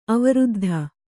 ♪ avaruddha